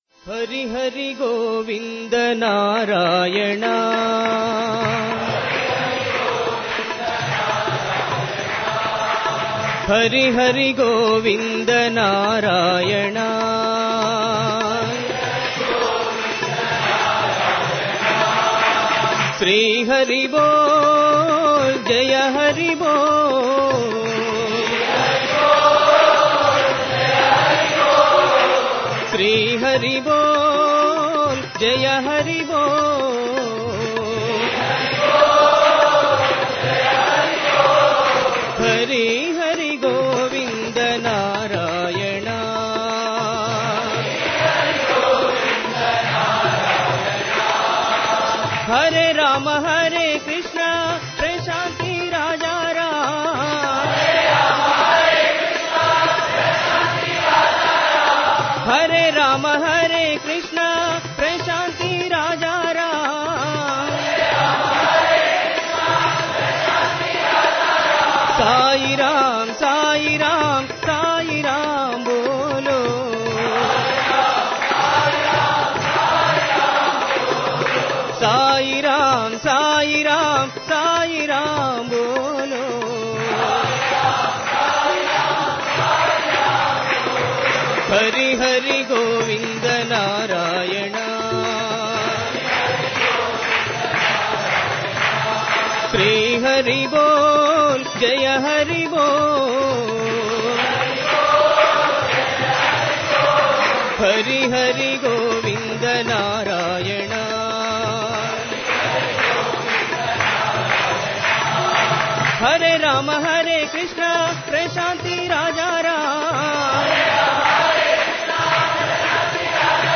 Devotional Songs
Sindhu Bhairavi / Bhairavi 8 Beat  Men - 5 Pancham  Women - 2 Pancham
Sindhu Bhairavi / Bhairavi
8 Beat / Keherwa / Adi
5 Pancham / G
2 Pancham / D